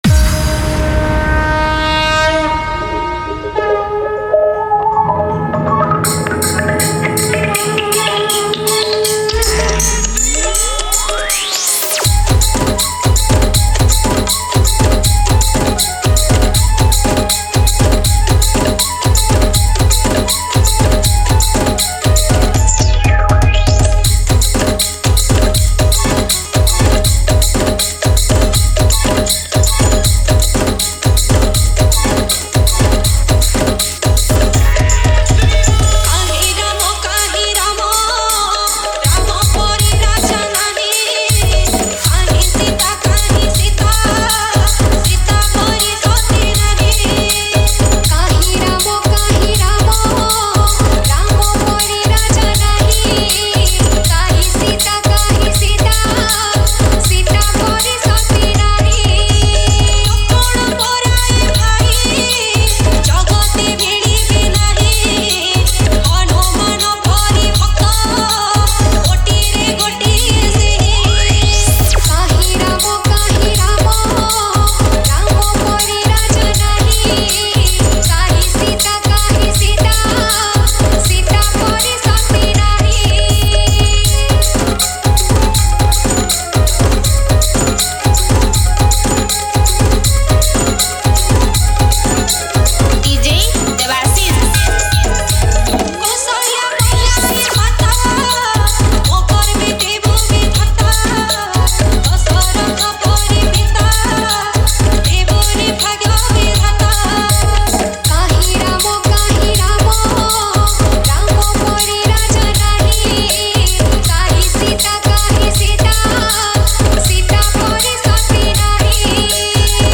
Bhajan Dj Song Collection 2022